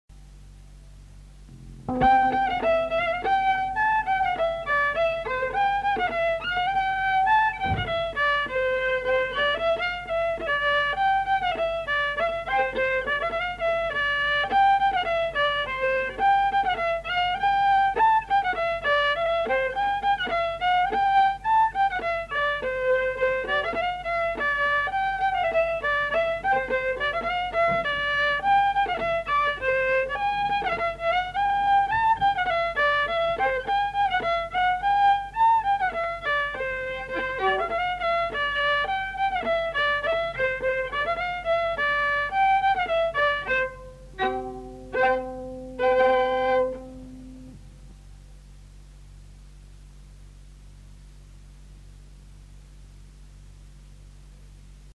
Aire culturelle : Haut-Agenais
Genre : morceau instrumental
Instrument de musique : violon
Danse : bourrée